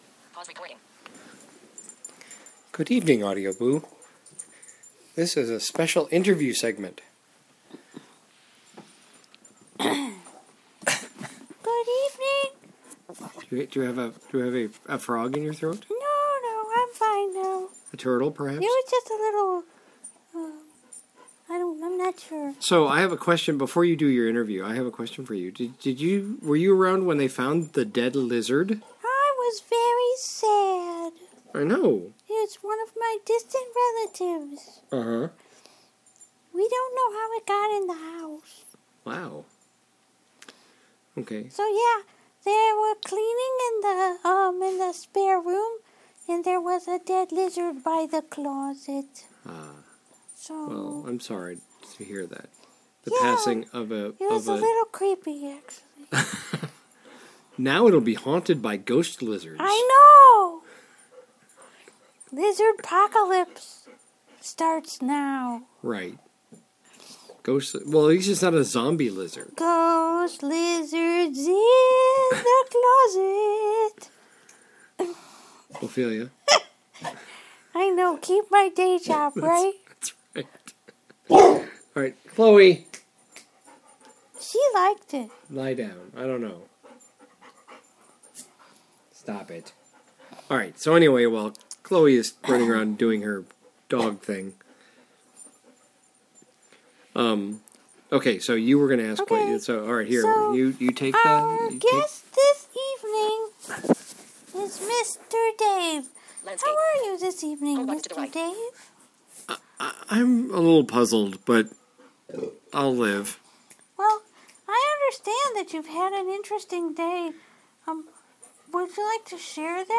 A strange day interview